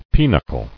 [pe·nuch·le]